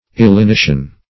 Illinition \Il`li*ni"tion\, n. [L. illinire, illinere, to